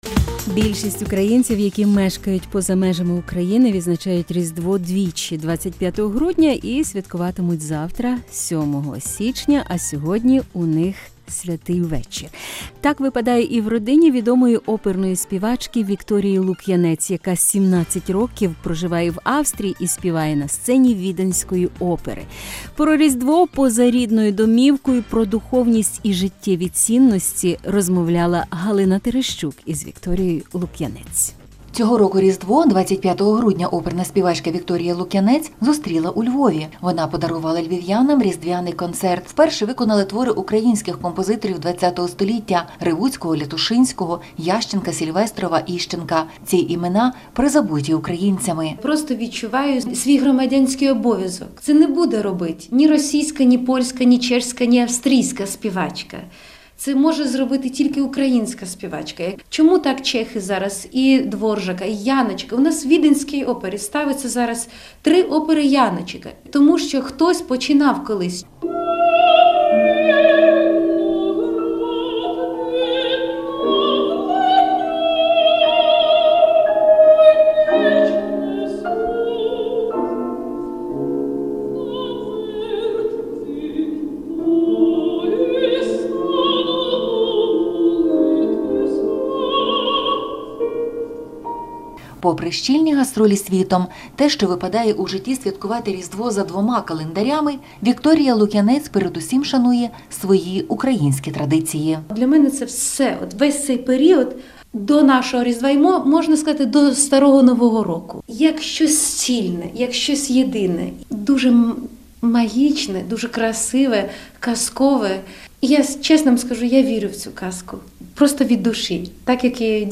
Tere+pryvitannia+vox pop-ss